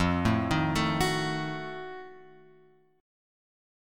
F7b9 chord {1 0 1 2 x 2} chord